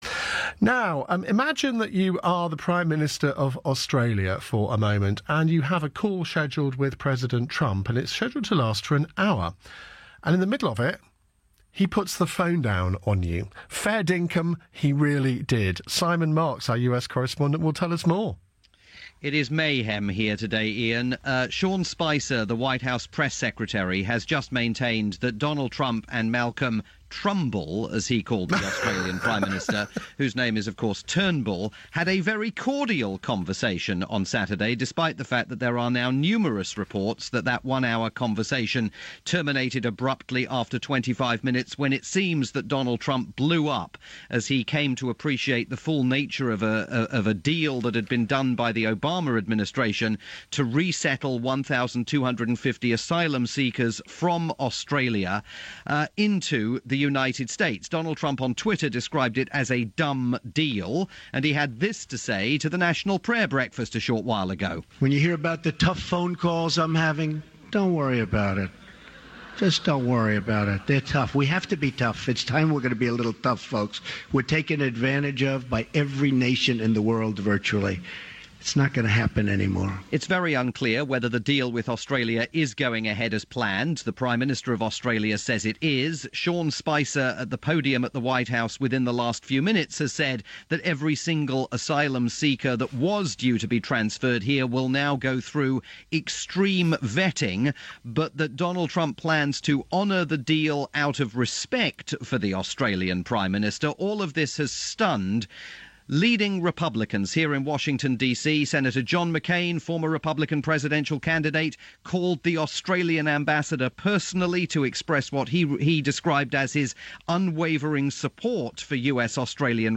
In a single day, President Trump has managed to infuriate the Australians, threaten the Iranians, and appeared to dilute sanctions in place against the Russians. My report via Iain Dale At Drive on the UK's LBC.